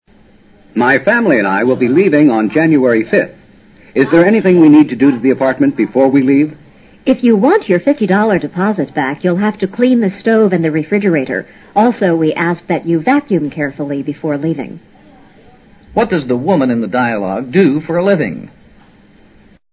这是住户与房产管理人员之间的对话。住户要迁居，询问搬走前要办些什么事。